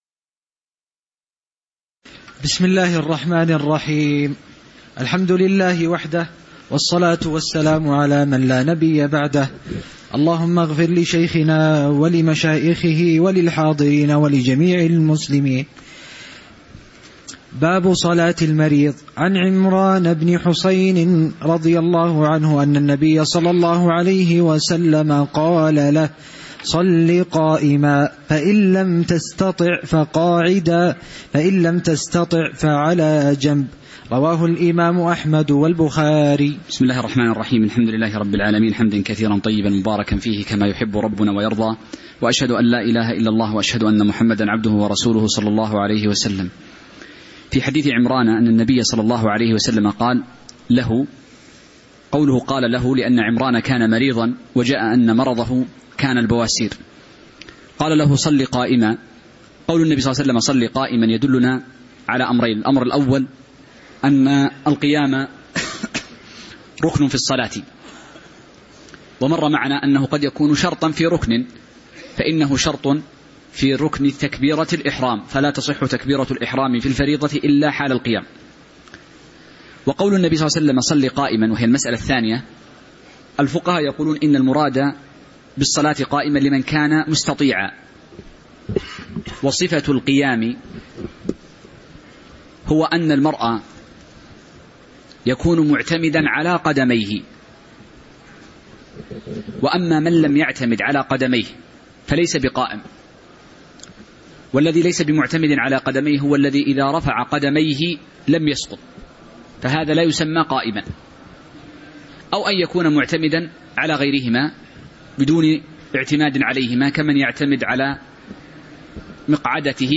تاريخ النشر ٢٥ جمادى الآخرة ١٤٤٠ هـ المكان: المسجد النبوي الشيخ